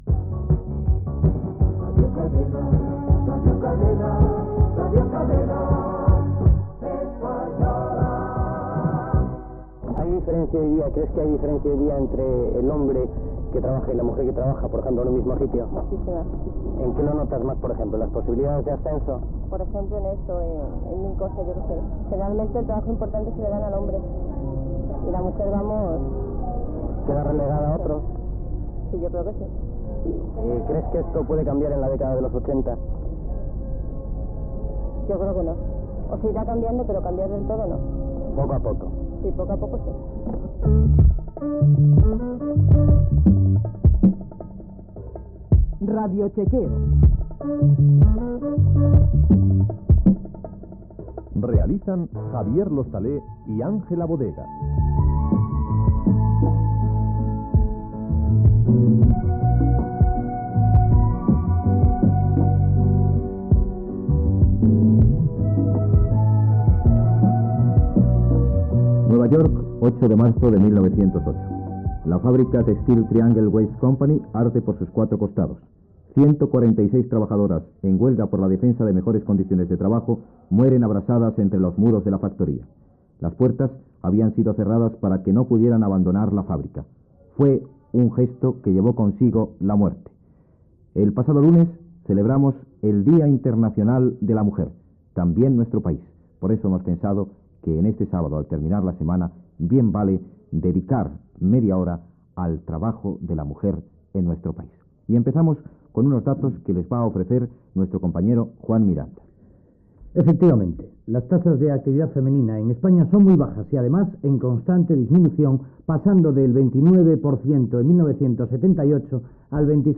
Indicatiu de la cadena, opinió d'una ciutadana, careta del programa, les dones en vaga que van morir en una fàbrica dels EE.UU l'any 1908